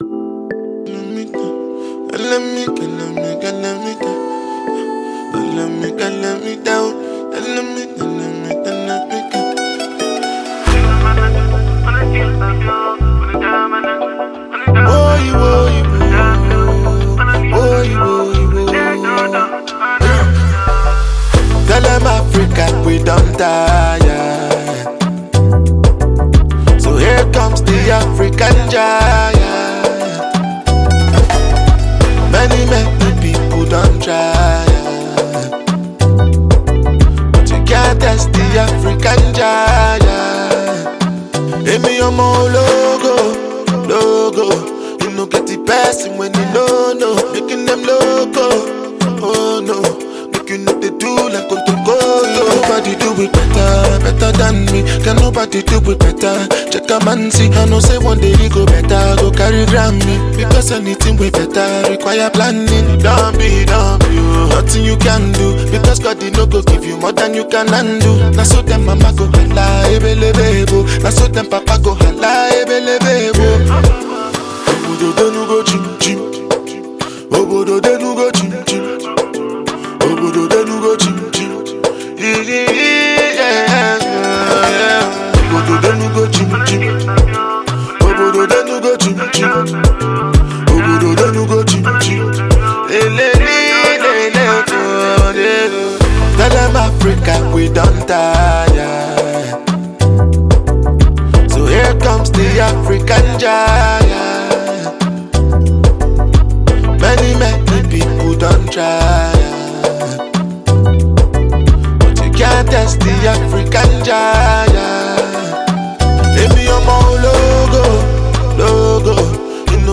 Released in 2019, it mixes Afrobeat, reggae, and hip-hop.